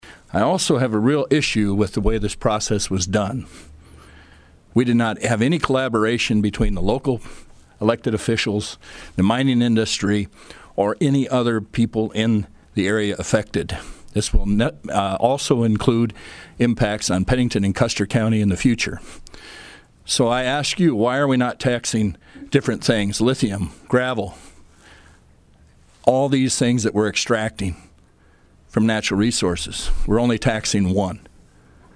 Dakota Gold is based out of Lead and representing the District in the South Dakota Senate is Senator Randy Deibert who questions the process.